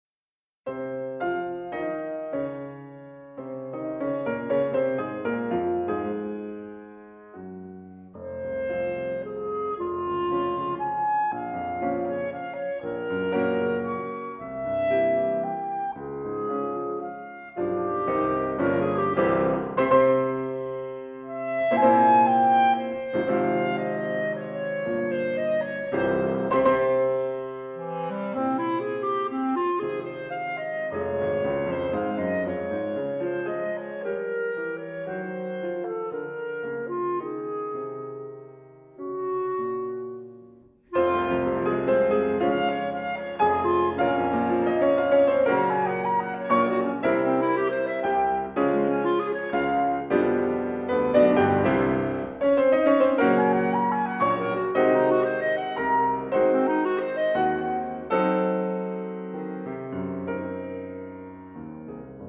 [2 Sonatas Op.120 for Clarinet
and Viola with Piano]
古典音樂